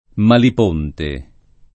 [ malip 1 nte ]